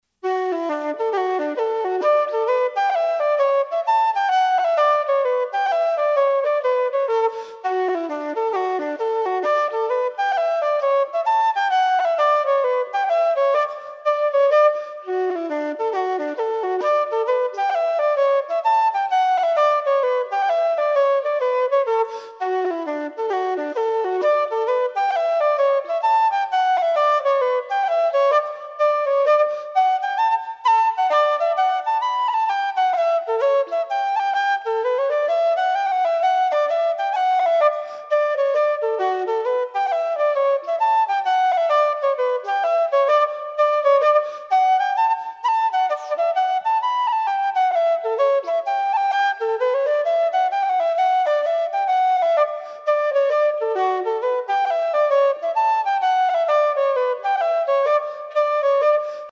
Traditional Irish Music -- Learning Resources Tailor's Twist, The (Hornpipe) /